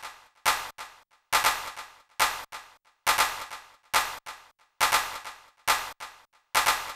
CLAP DELAY-L.wav